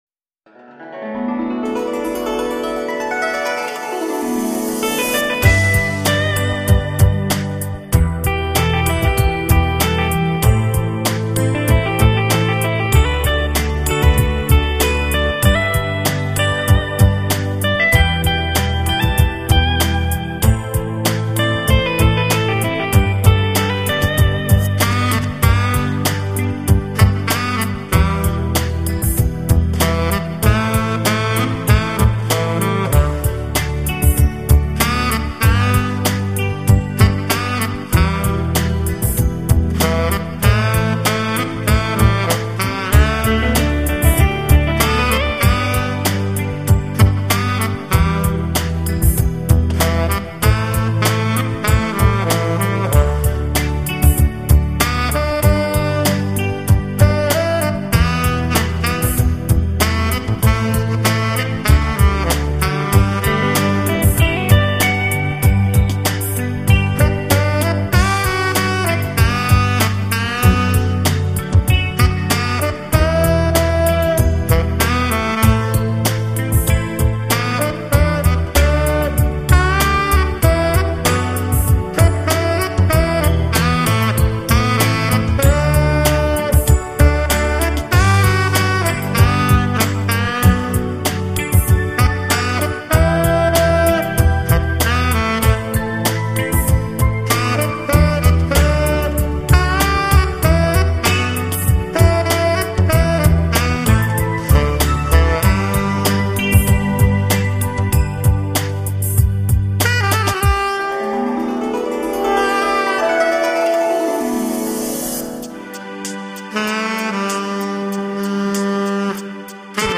萨 克 斯 简 介
这套双CD的萨克斯音乐，是以国内目前的流行歌曲改编而演奏的，听上去，